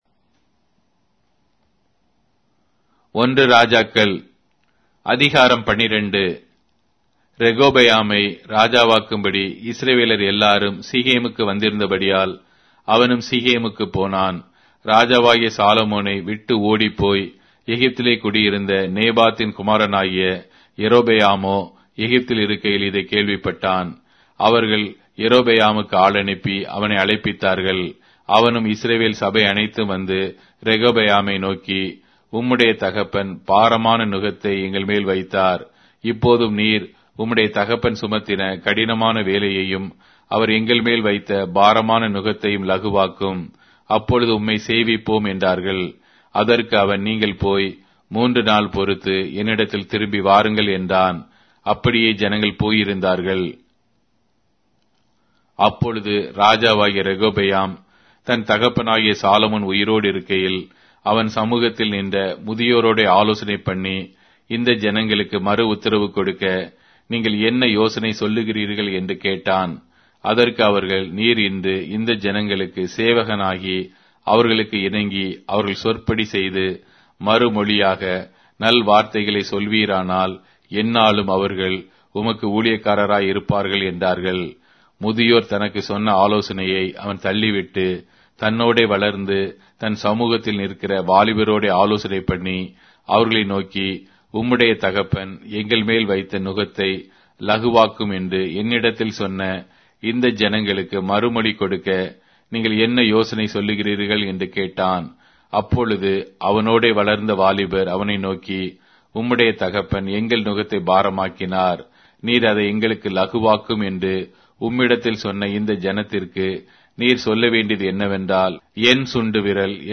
Tamil Audio Bible - 1-Kings 8 in Rv bible version